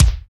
SI2 VFX KICK.wav